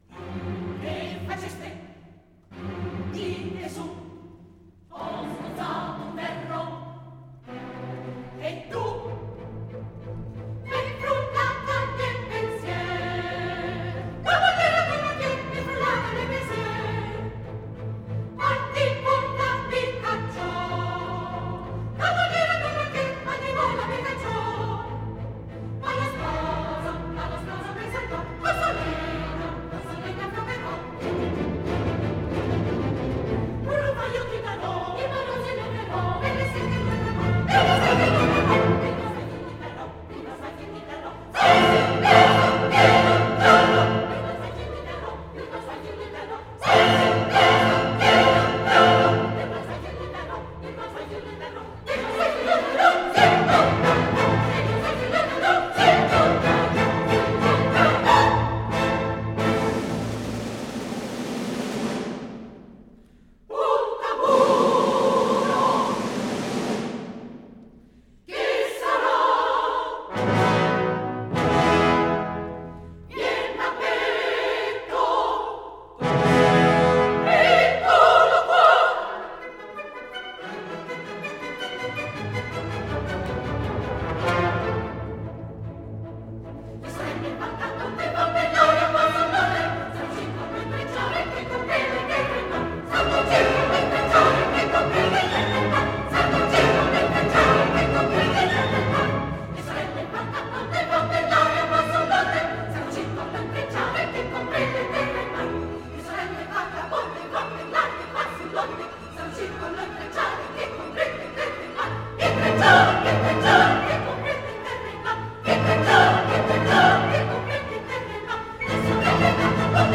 Два примера: 1) Чистый сигнал 2) Обработанный моим "реалфонсом" Мы же слушаем работу моего прибора, а не RealPhones